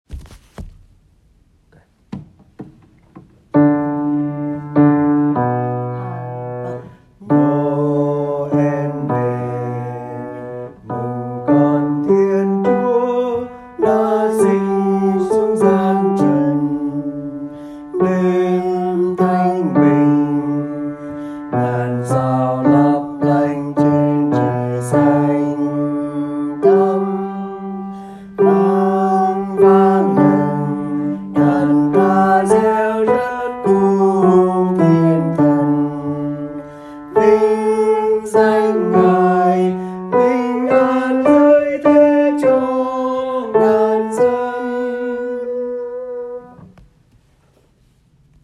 Bè Nam